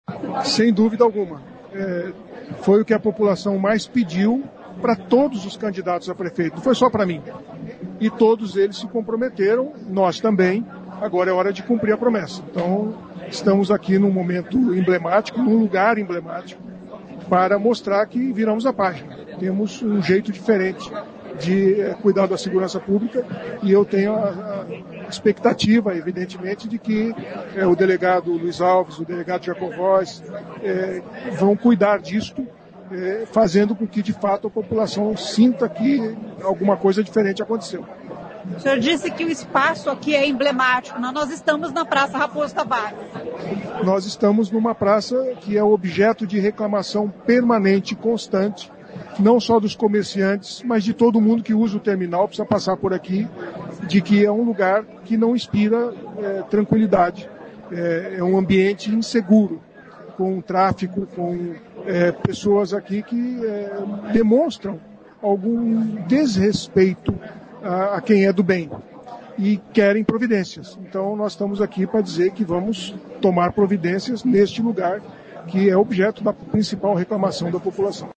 O prefeito Sílvio Barros falou durante a cerimônia que segurança foi um dos temas mais debatidos e cobrados dos candidatos durante as eleições municipais de 2024.